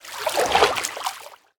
minecraft / sounds / liquid / swim5.ogg
swim5.ogg